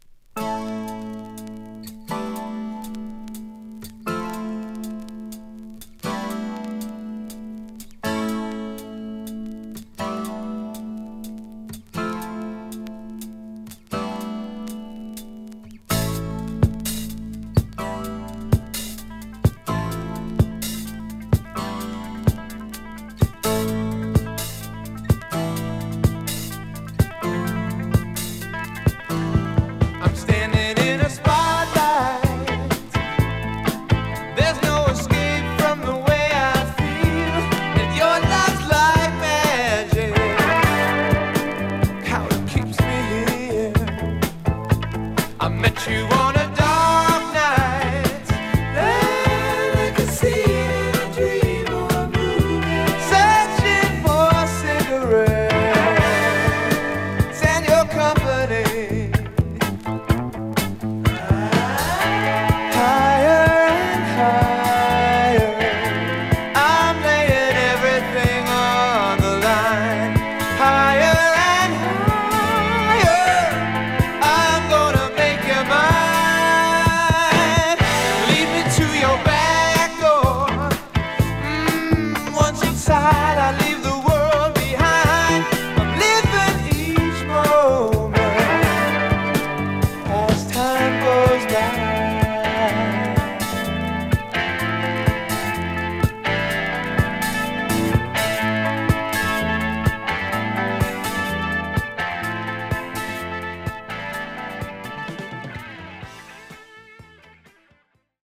まさに名演と呼ぶにふさわしい演奏で、ロック・ナンバーからバラード・ナンバーまで最高のグルーヴを作り上げています。